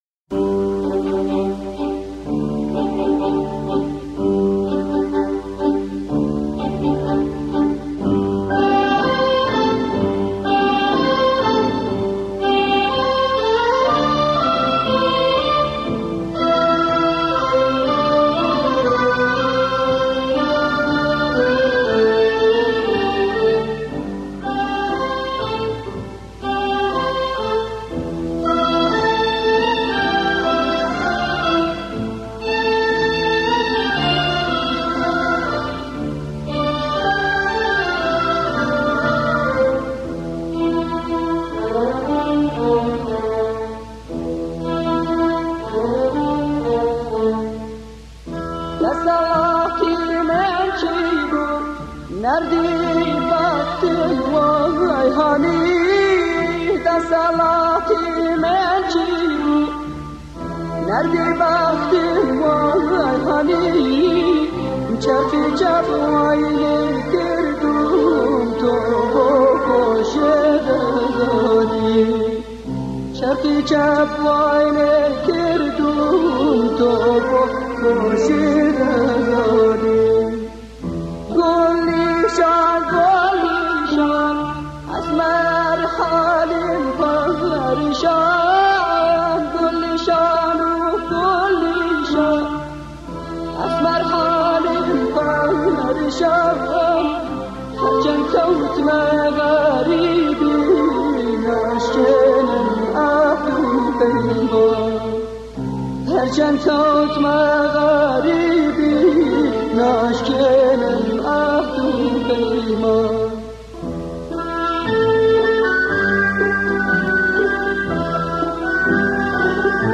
آهنگ زیبای کردی